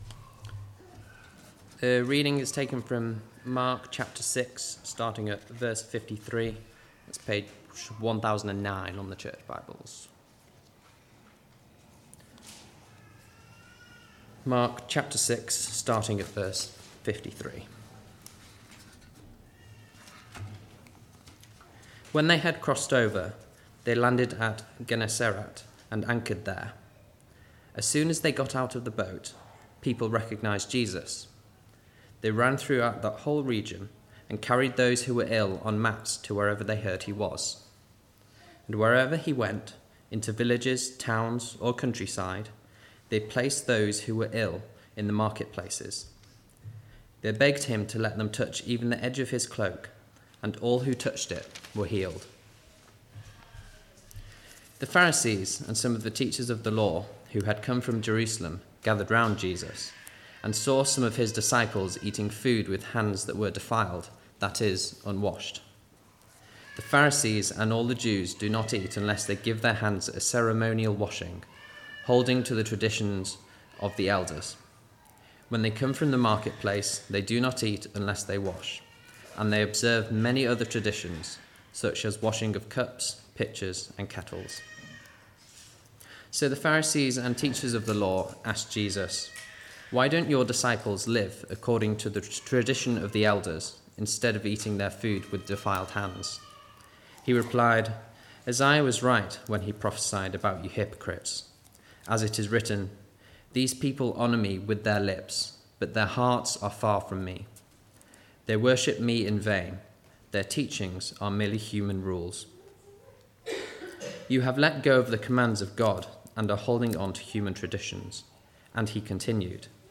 Mark 6:53–7:23 Service Type: Weekly Service at 4pm « “Take courage.